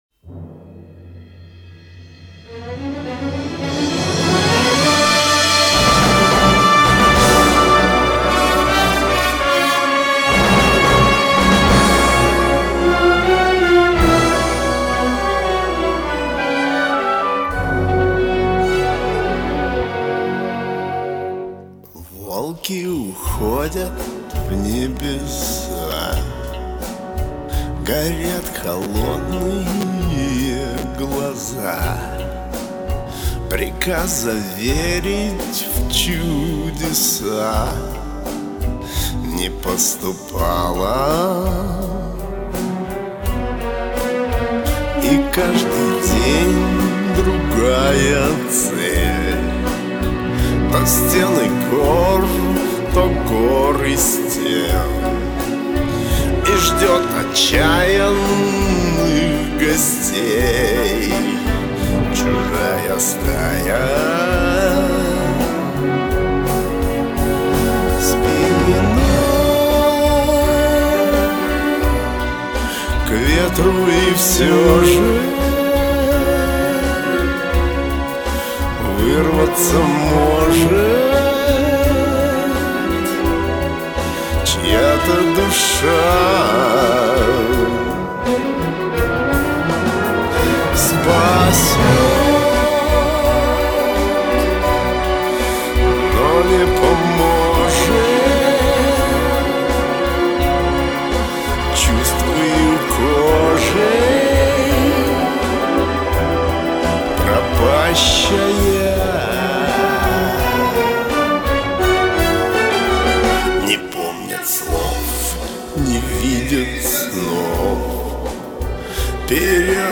С собой! Я всегда пою с собой!